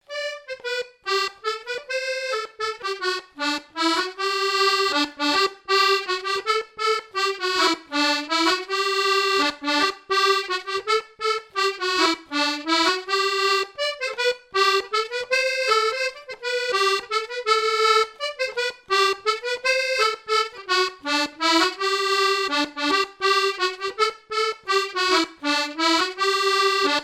Chants brefs - A danser
danse : mazurka
Répertoire à l'accordéon diatonique
Pièce musicale inédite